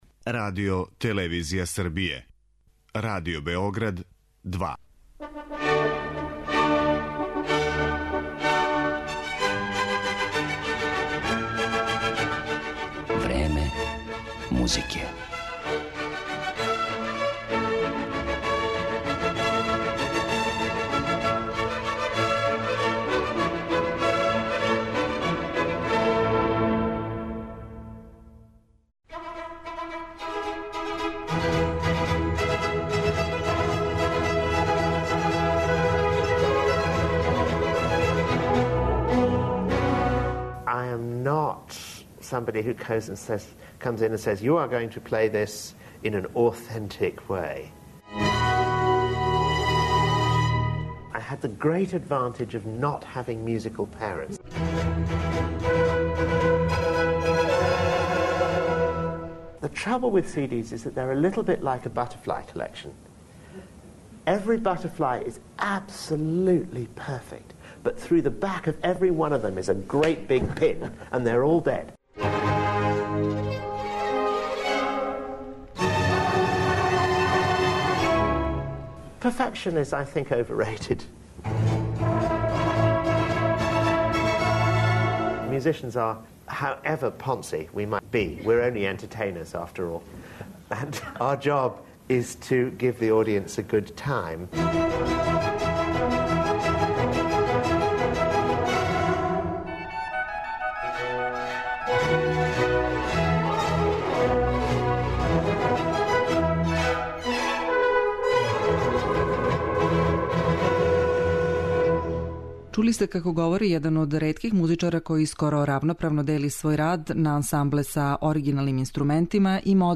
Данас ћемо га ипак представити у интерпретацијама литературе са којом се прославио и слушаћемо како тумачи дела барокних мајстора (Рамоа, Хендла, Баха, Матесона, Грауна и Корелија).